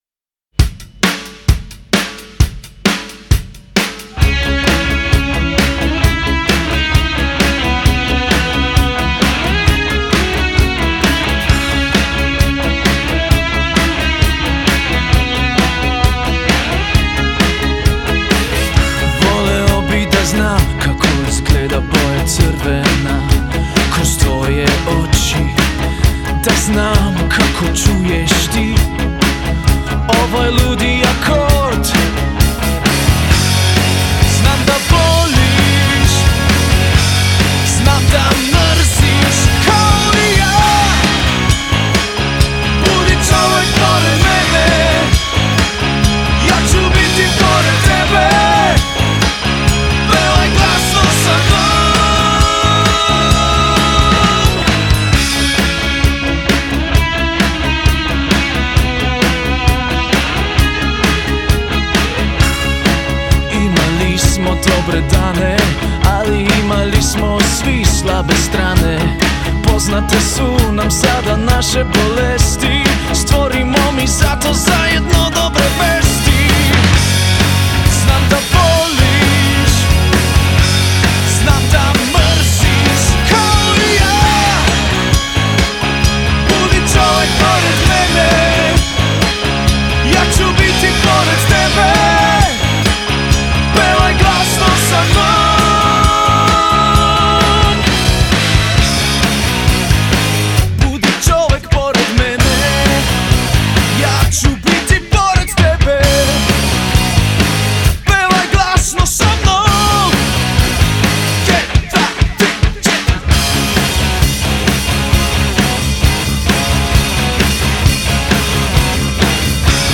je “zrela rock zasedba